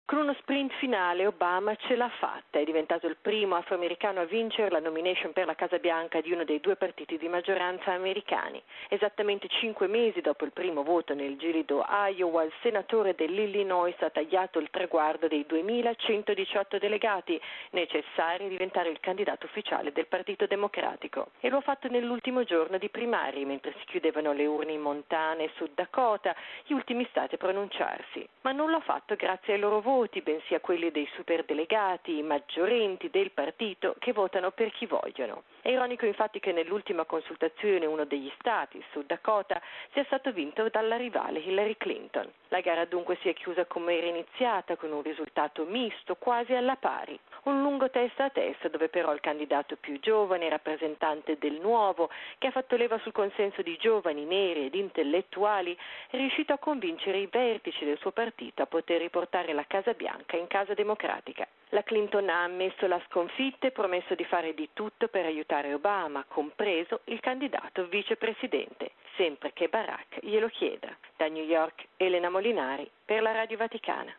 Il senatore democratico dell’Illinois è diventato il primo afroamericano a vincere la nomination per la Casa Bianca in uno dei due maggiori partiti del Paese. Il servizio